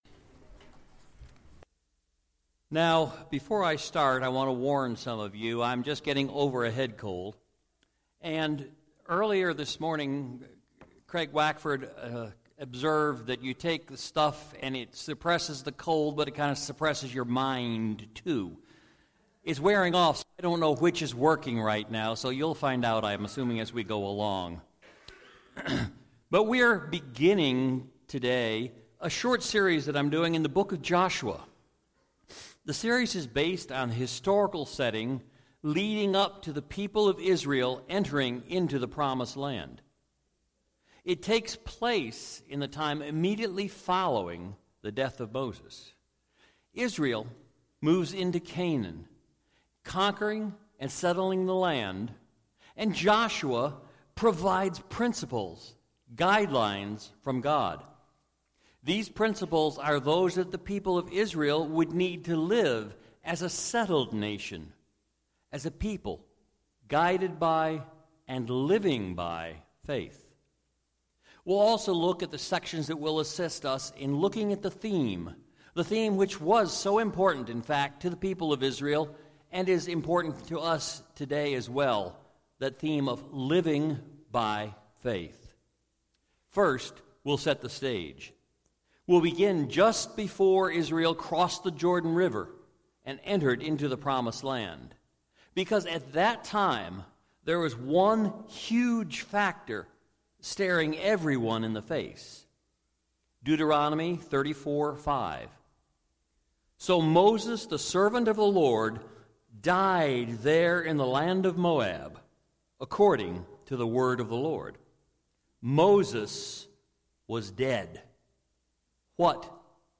Sermons - Calvary Baptist Bel Air